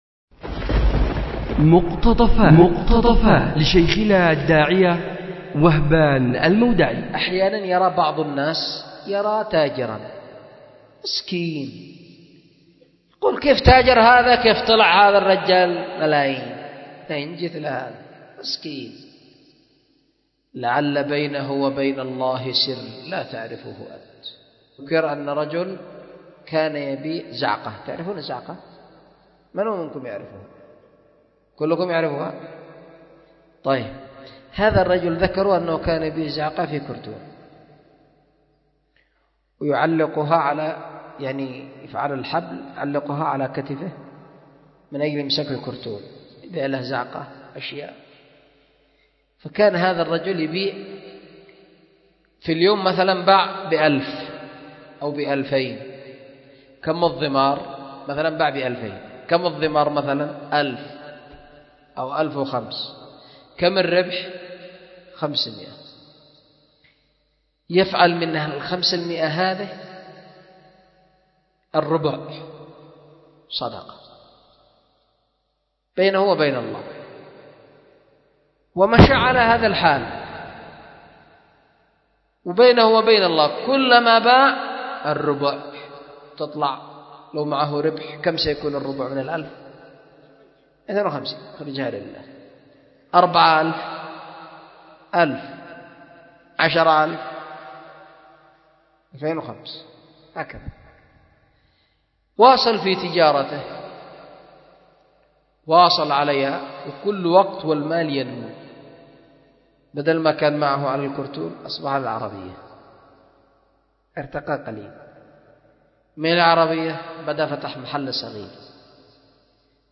مقتطف من درس
أُلقي بدار الحديث للعلوم الشرعية بمسجد ذي النورين ـ اليمن ـ ذمار